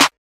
snare ~ sadboy.wav